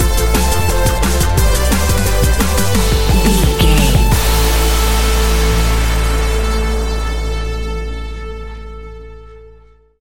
A great piece of royalty free music
Epic / Action
Fast paced
Aeolian/Minor
Fast
aggressive
dark
driving
energetic
futuristic
synthesiser
drum machine
electronic
sub bass